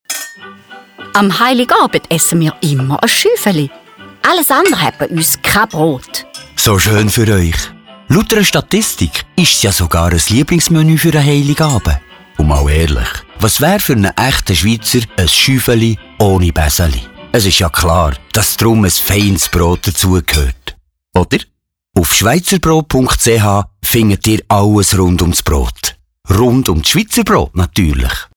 Kurze, humorvolle Hörspiele greifen dabei saisonale Ereignisse, Traditionen, Herkunft, Vielfalt oder Wertschöpfungskette von Schweizer Brot auf.
Radiospot Heiligabend